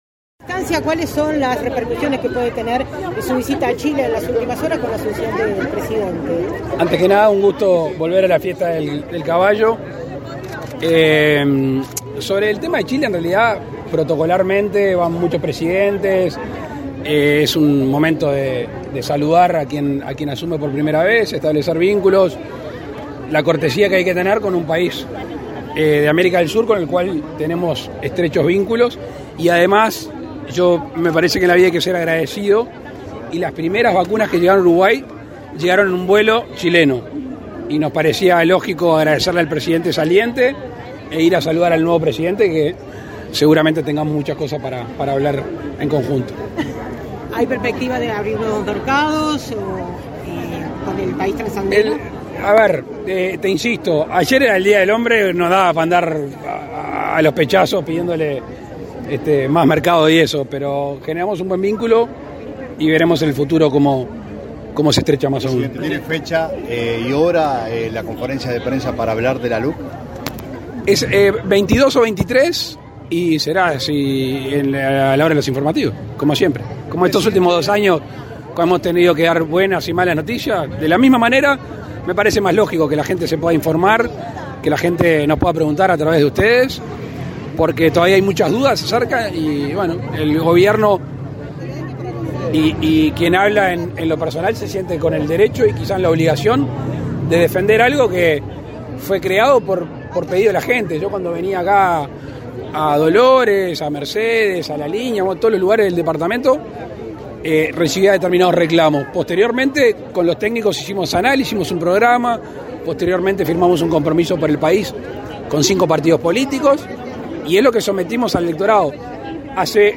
Declaraciones a la prensa del presidente de la República, Luis Lacalle Pou, en Dolores
Declaraciones a la prensa del presidente de la República, Luis Lacalle Pou, en Dolores 12/03/2022 Compartir Facebook X Copiar enlace WhatsApp LinkedIn El presidente Luis Lacalle Pou participó, este 12 de marzo, en la 33 .ª Fiesta del Caballo, en la ciudad de Dolores. En ese contexto, el mandatario efectuó declaraciones a la prensa.